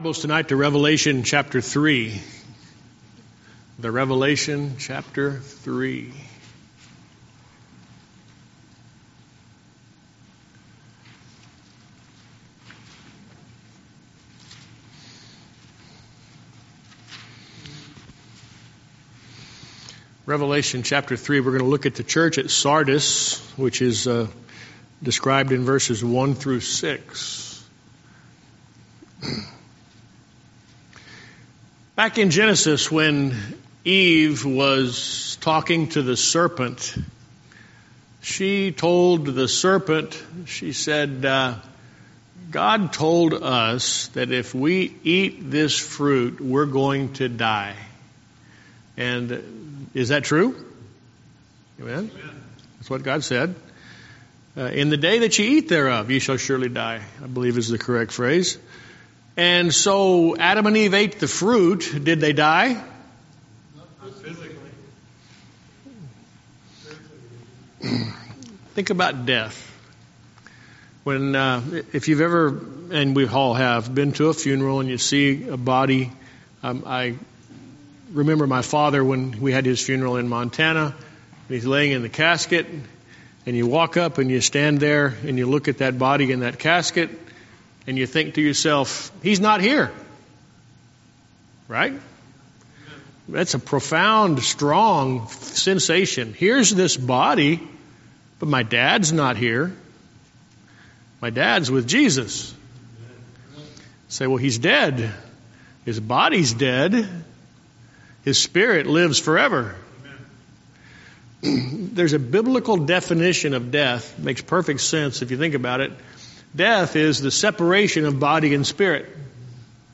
Series: Guest Speaker